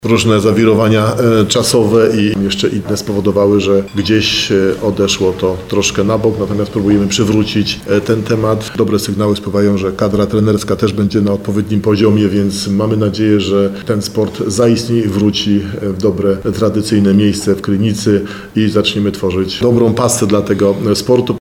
26starosta.mp3